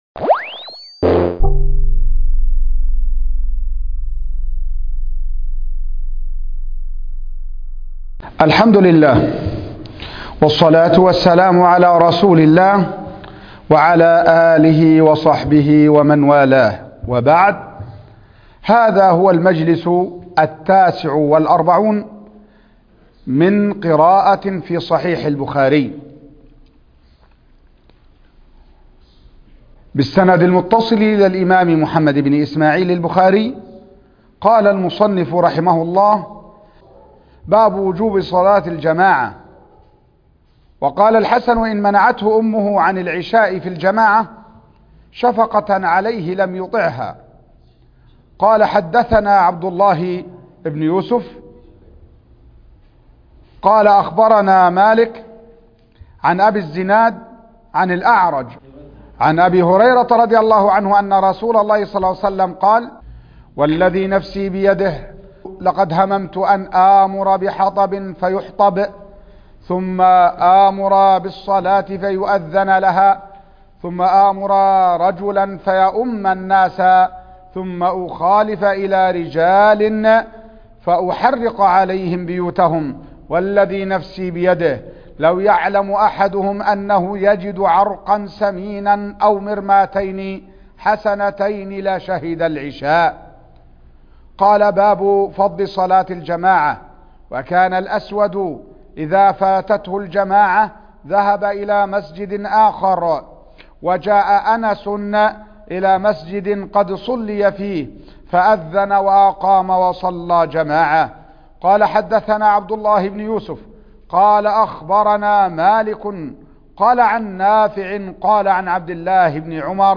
الدرس ( 49) قراءة صحيح البخاري